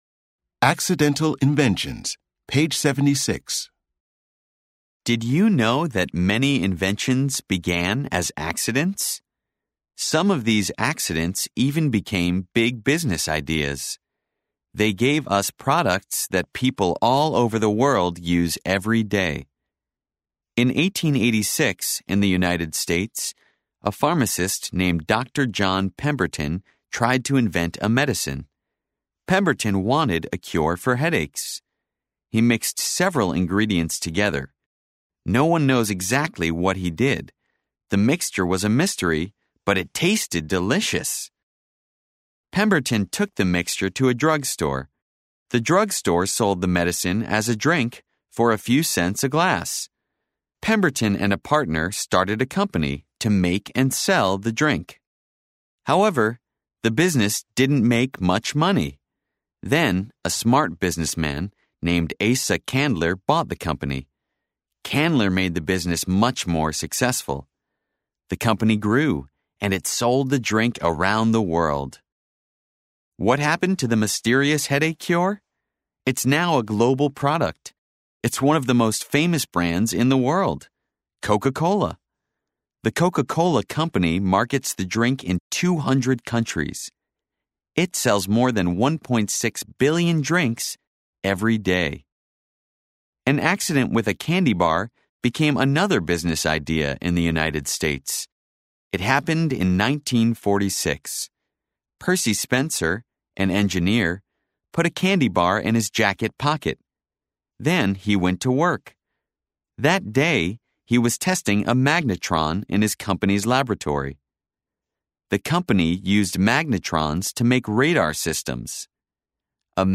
American English